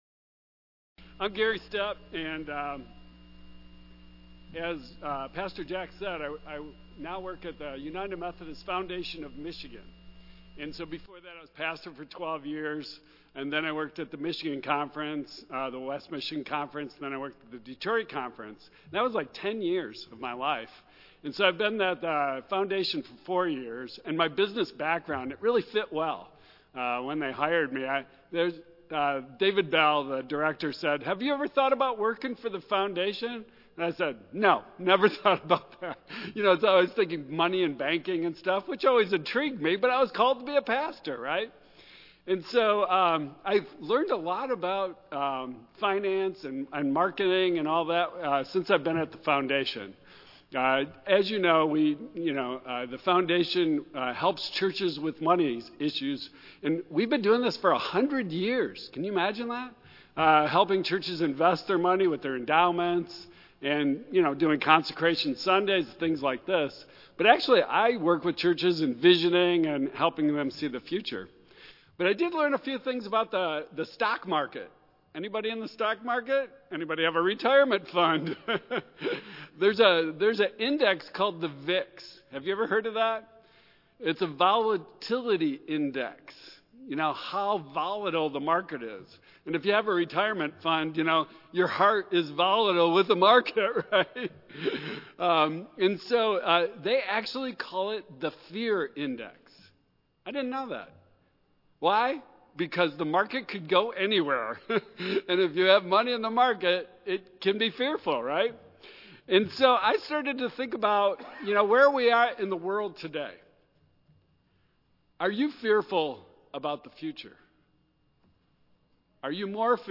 Consecration Sunday and Founder’s Day Message
Tagged with Central United Methodist Church , Michigan , Sermon , Waterford , Worship